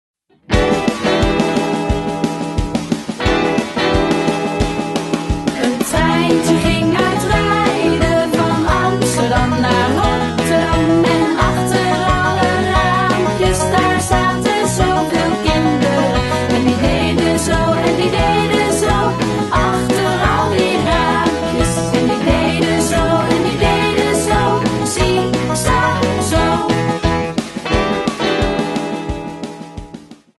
Liedje
Track-19-Een-treintje-ging-uit-rijden_vertraagd.mp3